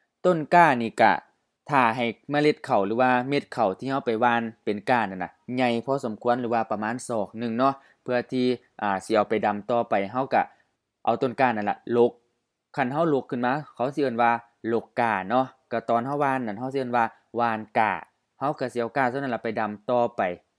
IsaanPronunciationTonesThaiEnglish/Notes
ต้นก้า ton-ga: HF-HF ต้นกล้า rice seedling
ก้า ga: HF กล้า seedling, rice seedling